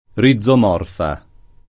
[ ri zz om 0 rfa ]